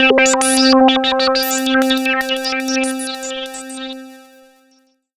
S AND H C4.wav